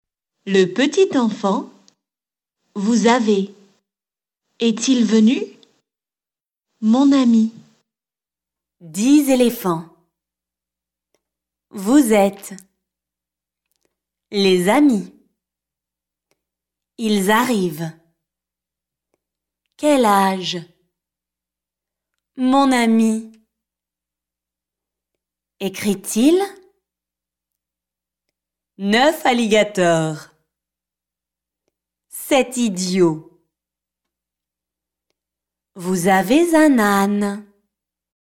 PRONONCIATION
A “liaison” occurs when the final consonant sound of one word is linked to the initial vowel sound of the next word.